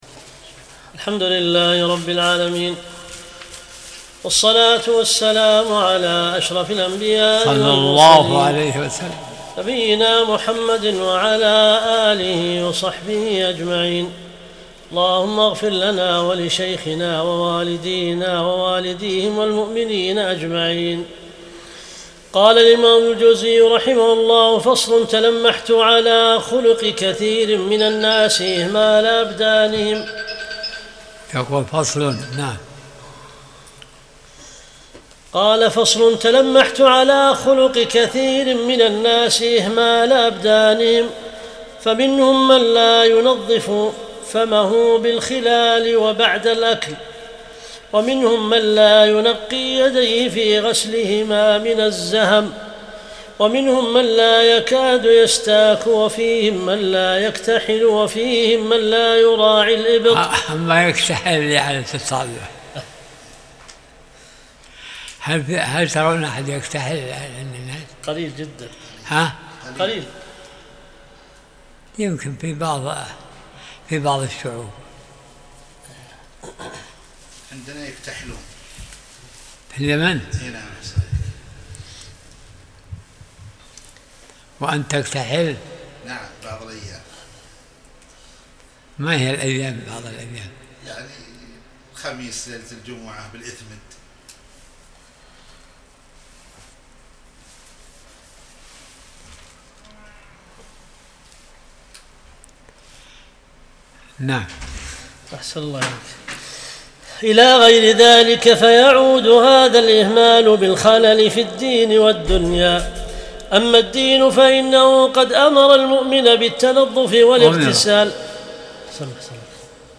درس الأربعاء 46